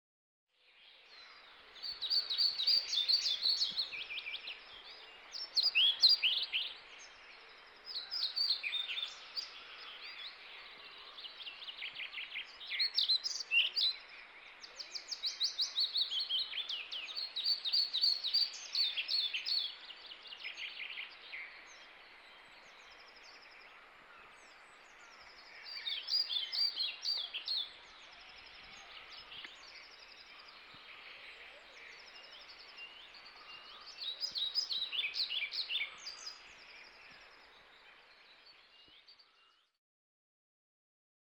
Kirjosieppo on pihapöntön uskollinen asukas